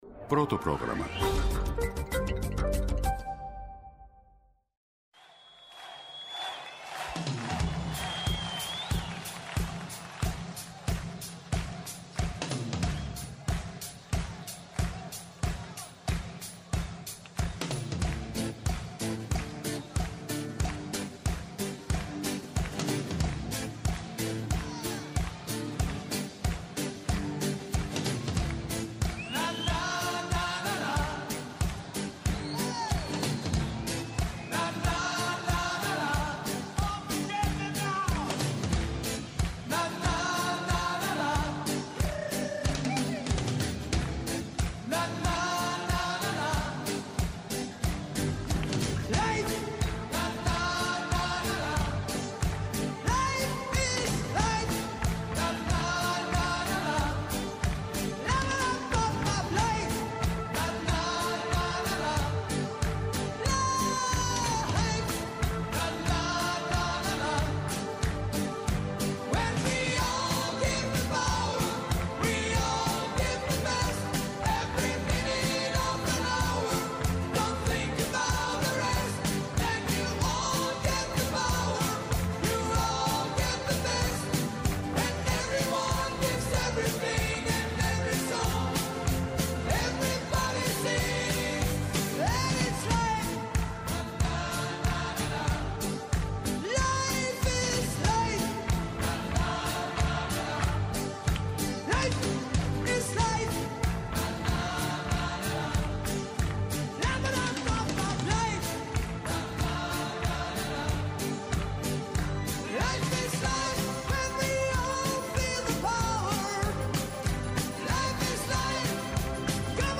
Εκτάκτως σήμερα 12.00 με 13.00 η εκπομπή “Σε Διάδραση”.